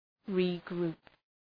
Shkrimi fonetik {rı’gru:p}